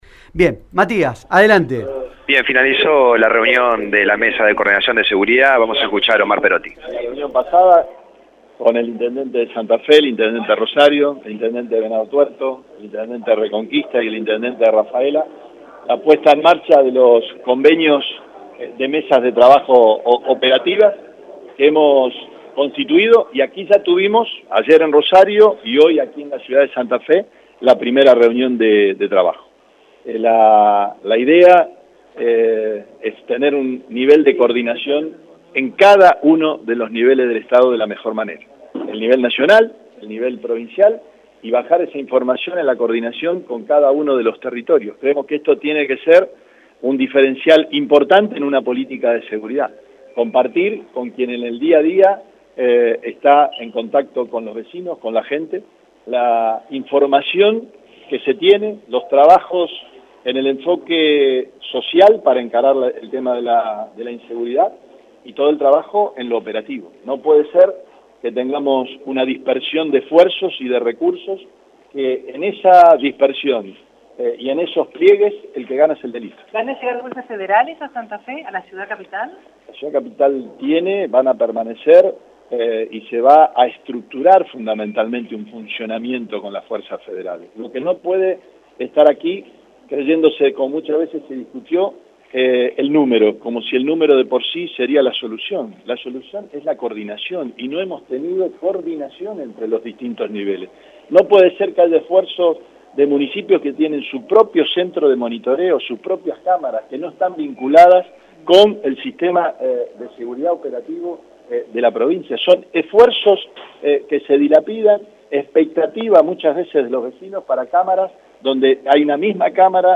LA-MAÑANA-MOVIL-EN-LA-MUNICIPALIDAD-SEGURIDAD-OMAR-PEROTTI-EMILIO-JATON-MARCELO-SAIN.mp3